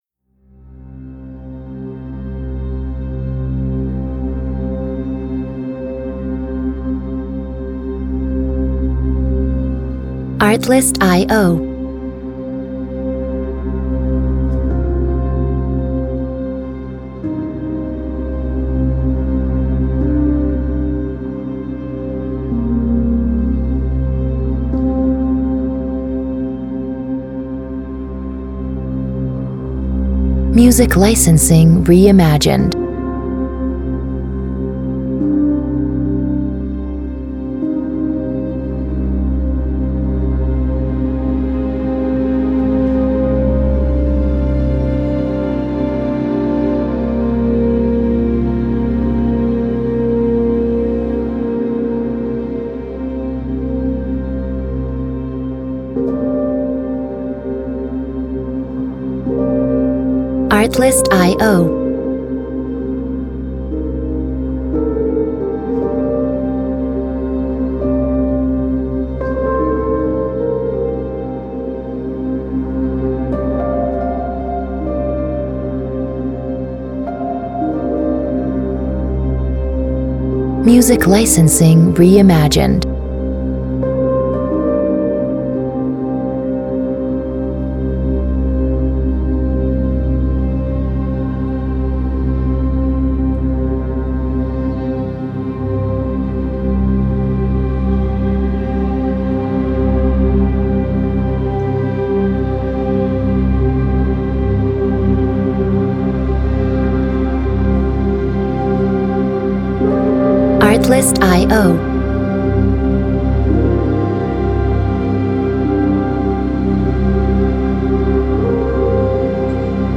A harrowing and affecting track, this one has all you need.